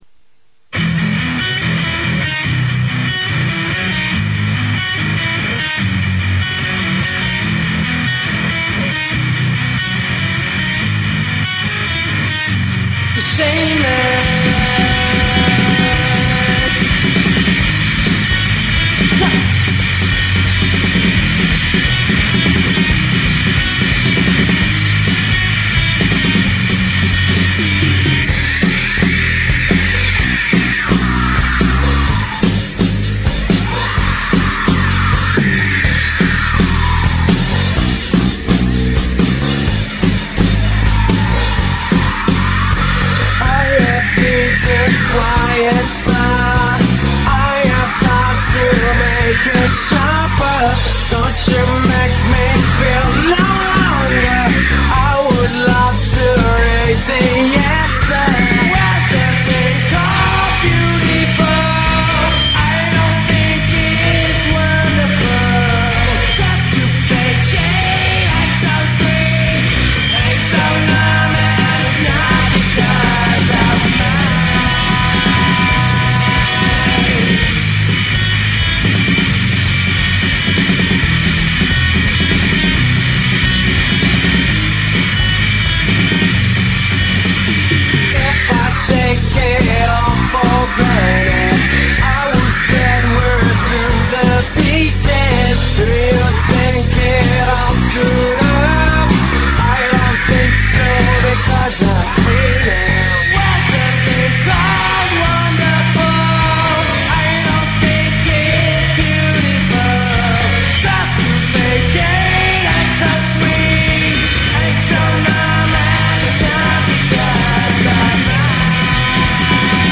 The best alternative rock band in Malaysia.
Just full-on rock! Great drumming.